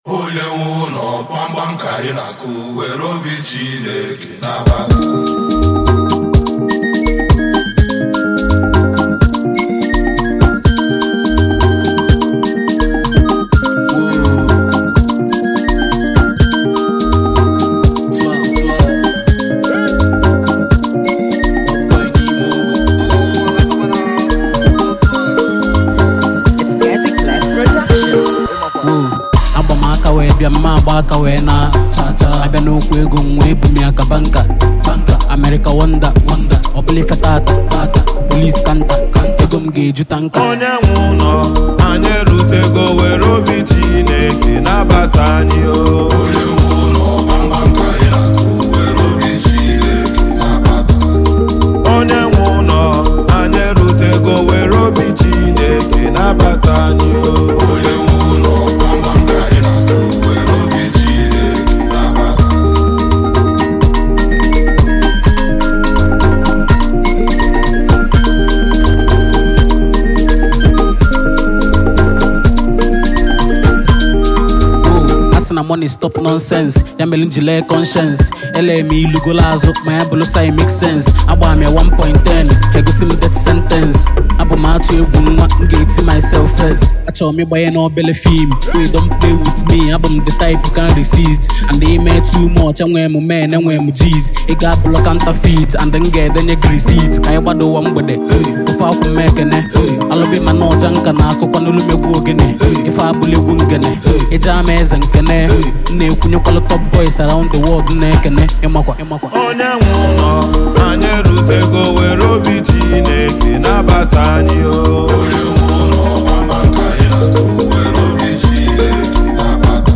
Highly rated Nigerian rapper and performer